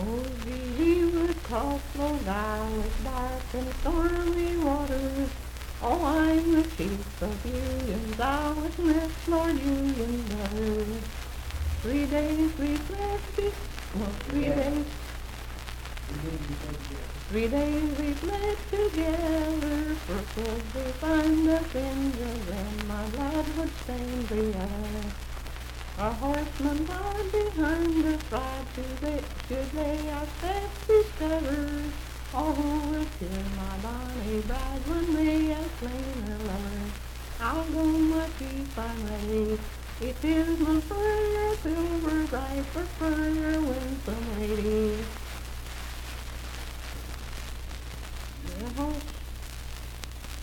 Unaccompanied vocal music
Verse-refrain 3(2-4).
Voice (sung)
Hardy County (W. Va.), Moorefield (W. Va.)